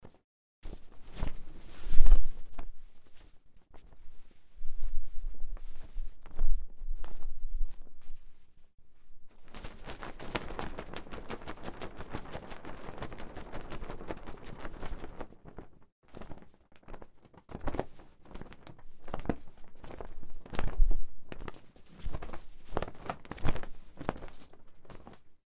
Звуки флага
Флаг на крыше здания